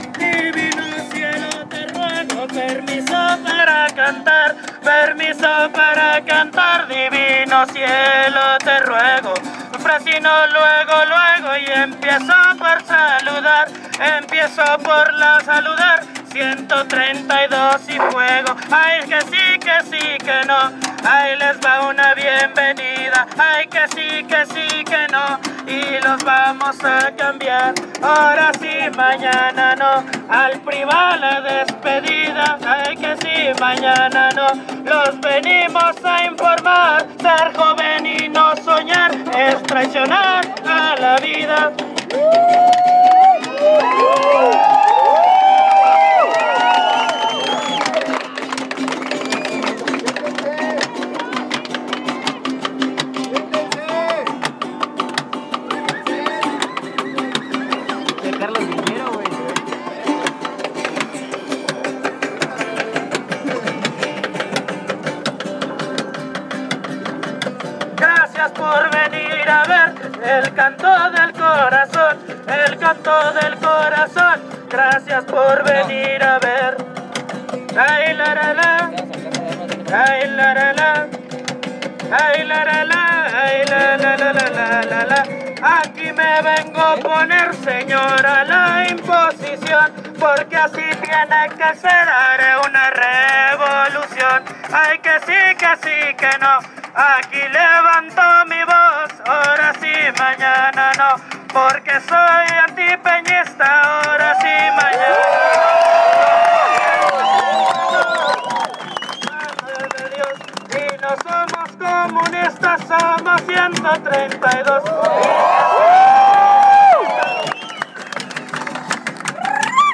Música de marcha